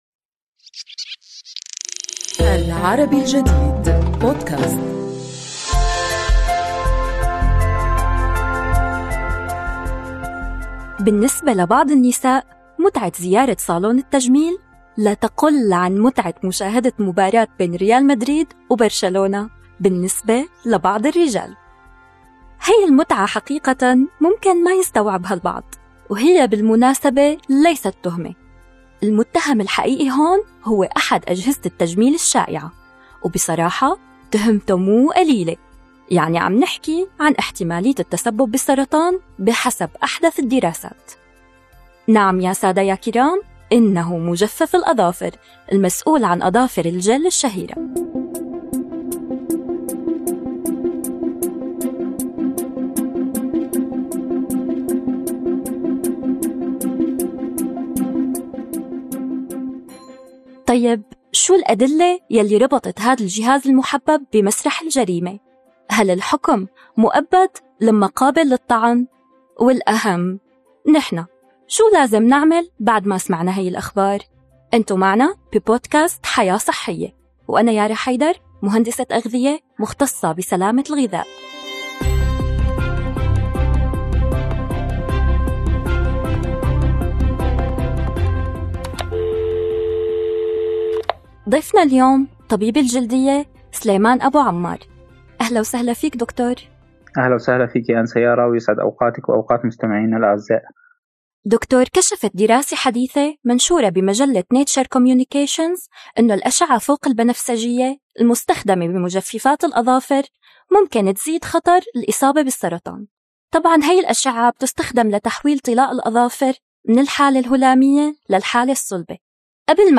ضيفنا في هذه الحلقة طبيب الجلدية والتجميل